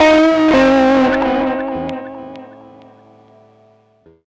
Track 15 - Guitar 02.wav